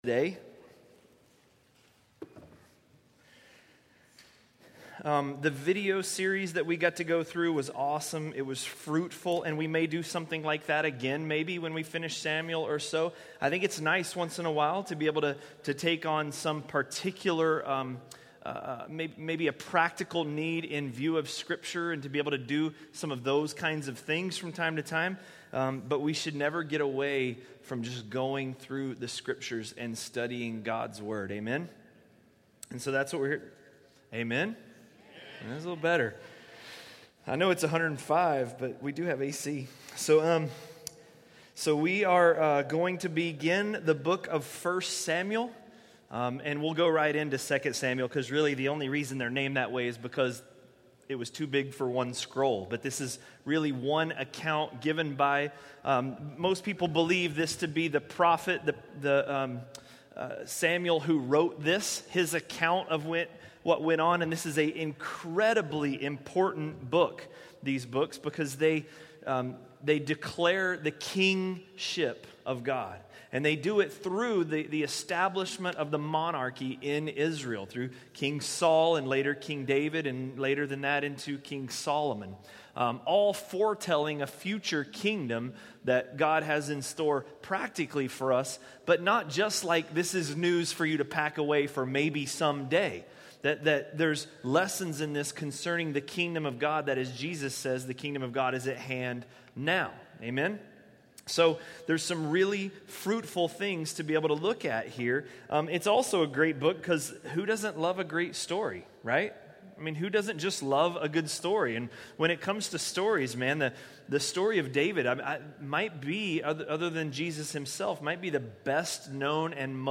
A message from the series "1 Samuel." 1 Samuel 1:1–2:10